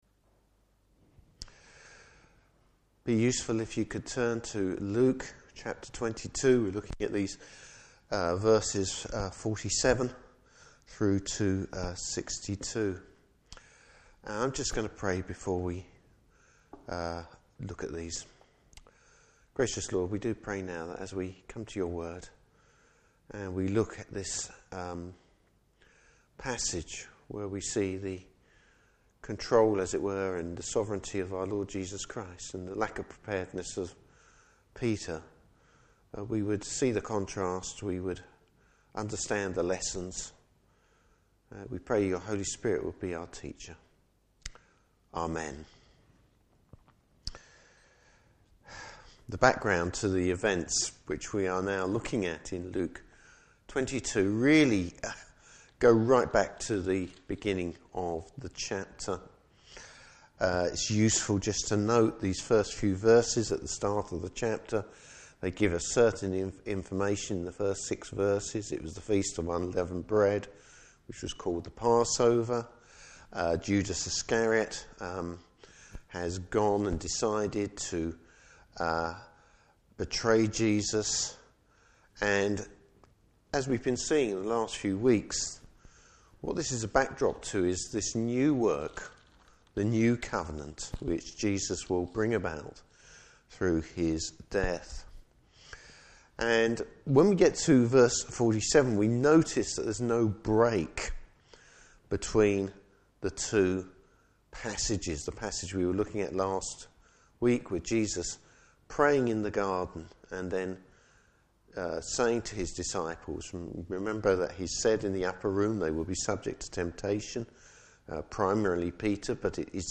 Service Type: Morning Service The contrast between Jesus and Peter.